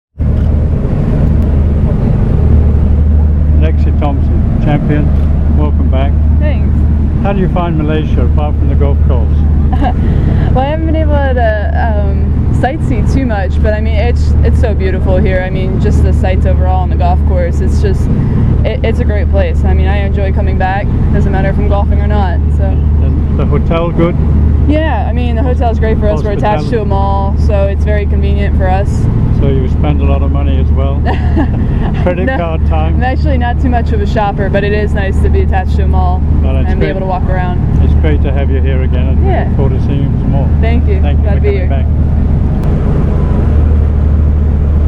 at the Sime Darby LPGA Malaysia 2014.